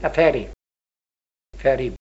the-phonology-of-rhondda-valleys-english.pdf
3_10.2._fairy...ferry.mp3